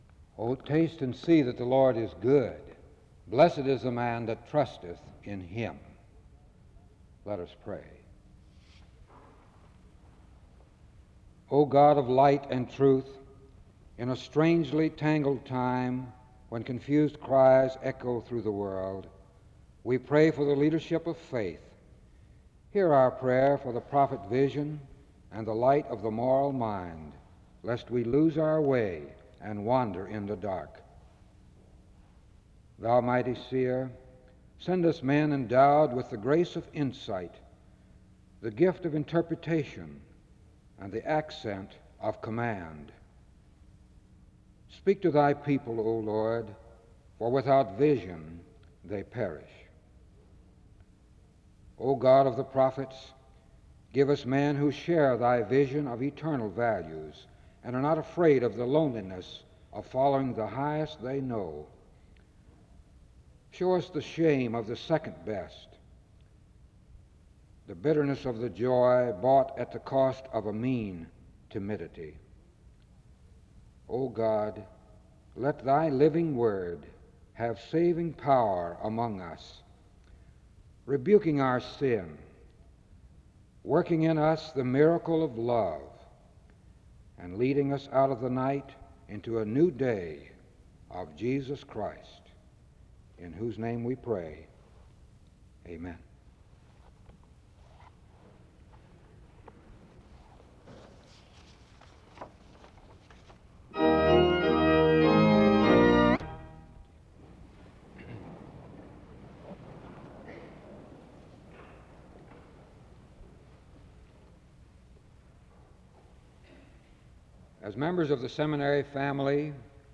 The service begins with a prayer from 0:00-1:36.
There are closing remarks, announcements, and prayer from 46:46-47:52.
SEBTS Chapel and Special Event Recordings